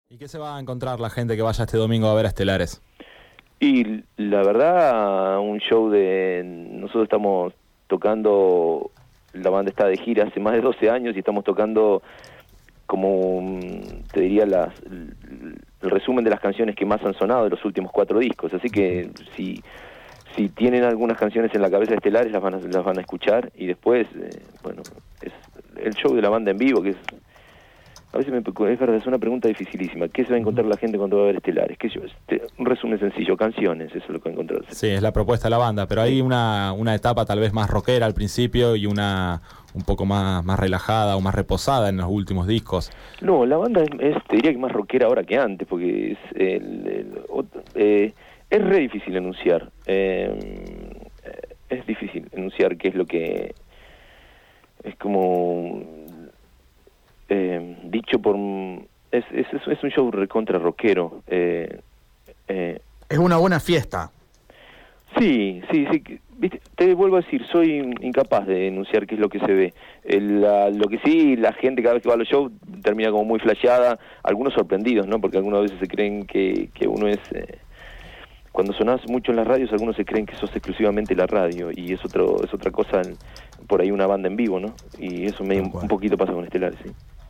Desde su casa de Villa Elisa, cerca de La Plata, el cantante de Estelares dialogó con Pausa en el Aire para adelantar su show de este domingo en Santa Fe. Pero, además, conversó sobre el nuevo disco que vienen a presentar, sobre sus experiencias de vida de rockero y sobre una nueva etapa signada por la “intensidad de la dicha”.